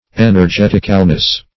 En`er*get"ic*al*ness, n.